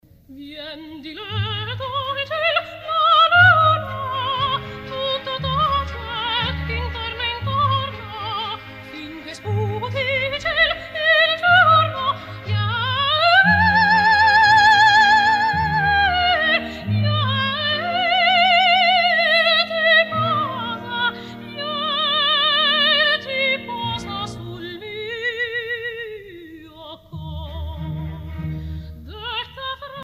digitally restored